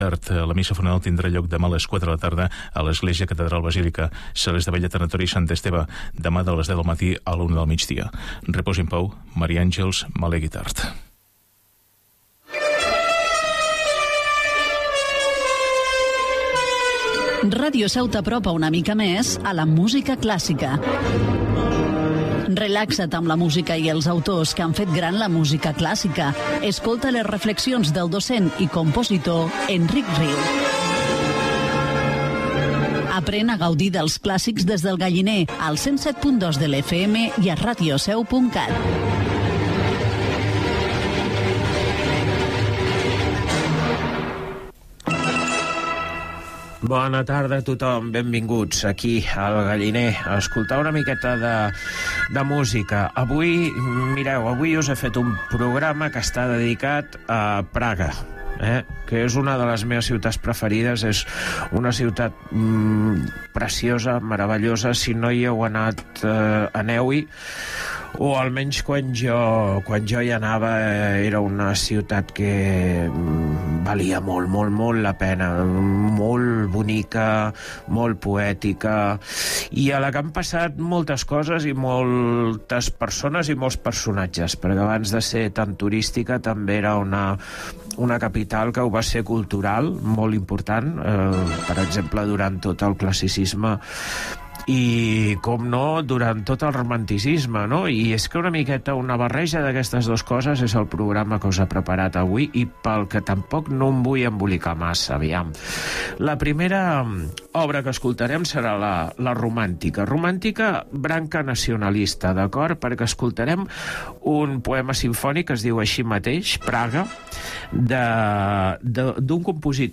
Programa de música clàssica